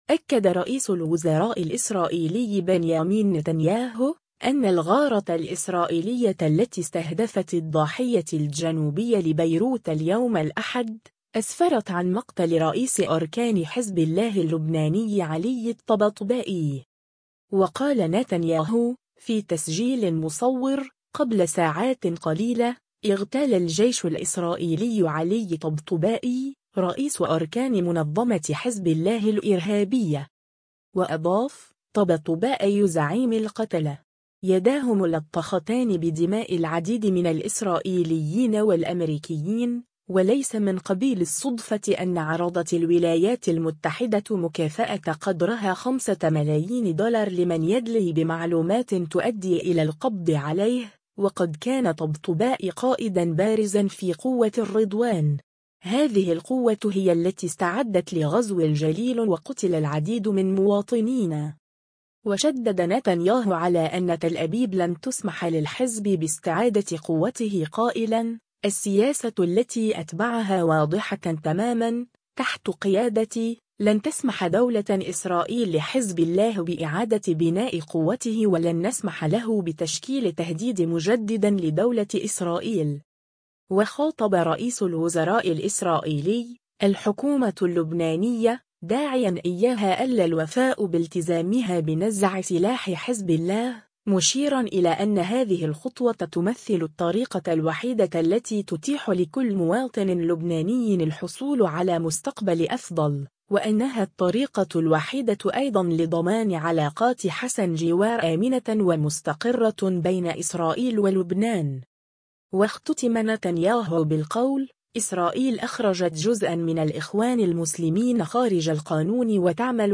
و قال نتنياهو، في تسجيل مصور : “قبل ساعات قليلة، اغتال الجيش الإسرائيلي علي طبطبائي، رئيس أركان منظمة حزب الله الإرهابية”.